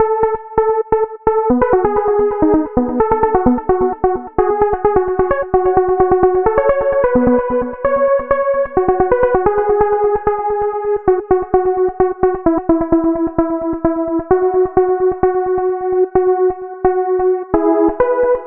加沙 " 建筑工地打桩机附近的瓦砾 阿拉伯之声1 加沙 2016年
描述：建筑工地桩司机瓦砾附近阿拉伯语voices1加沙2016.wav
Tag: 施工 打桩机 工地 司机 瓦砾